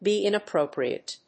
be+inappropriate.mp3